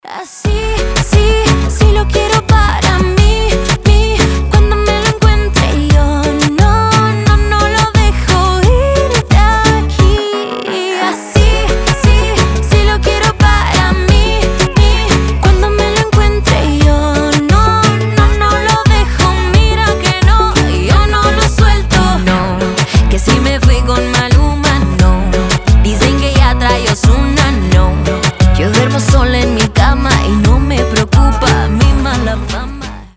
гитара
ритмичные
заводные
Reggaeton
Latin Pop